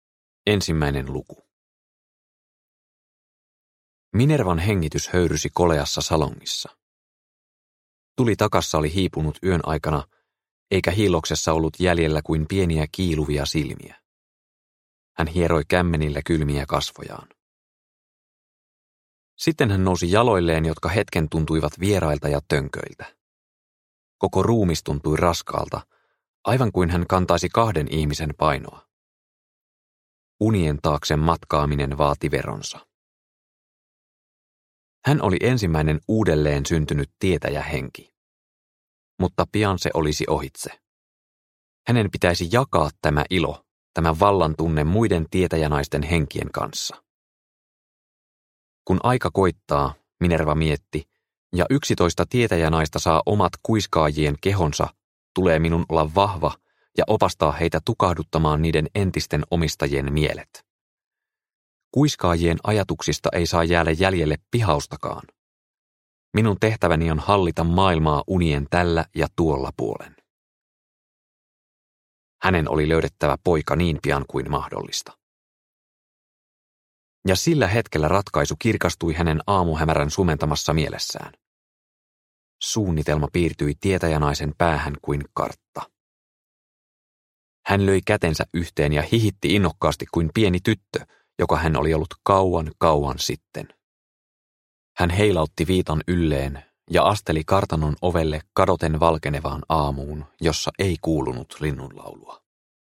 Ikimaa - Kuiskaajien kilta – Ljudbok – Laddas ner